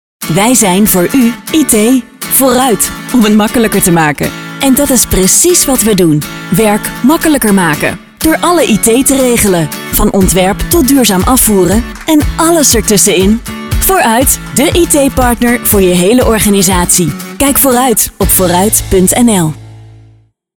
Vooruit als radiocommercial
Onze campagne is ook te horen als een radiocommercial op BNR Nieuwsradio en in populaire zakelijke podcasts.